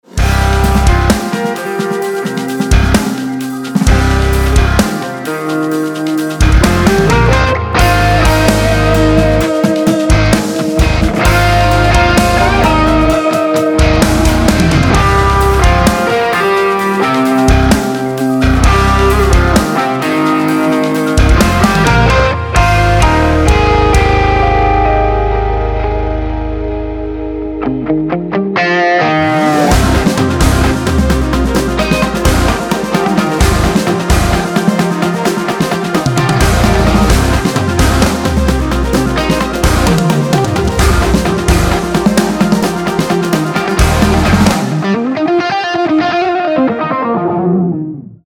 без слов
электрогитара
Виртуозная игра на электрогитаре